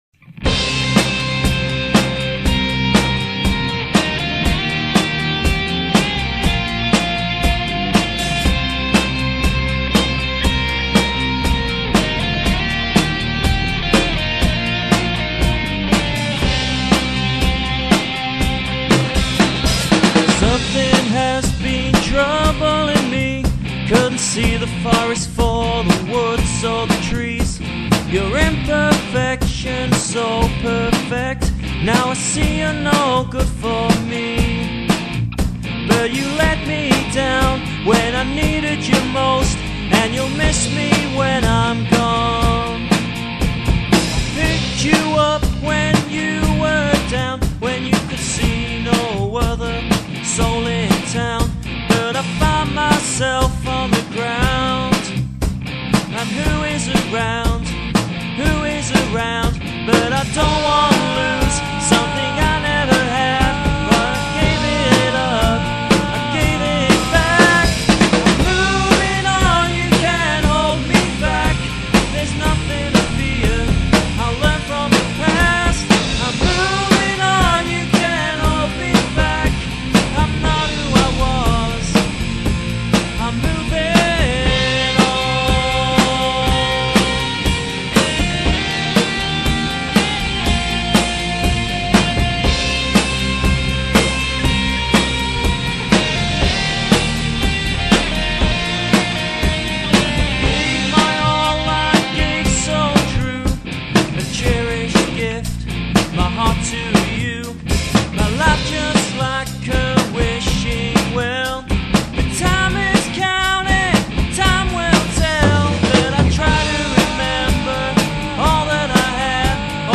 Heavy metal
Rock & Roll
Prog rock